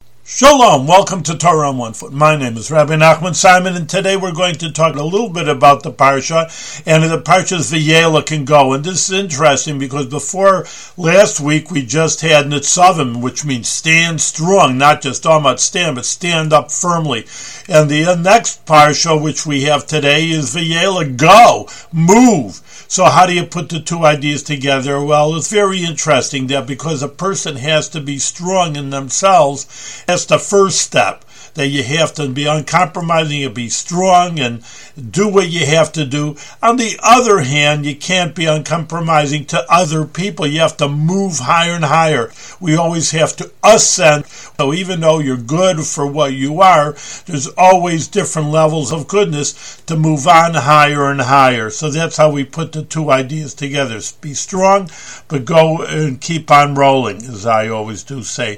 One-minute audio lessons on special points from weekly Torah readings in the Book of Deuteronomy.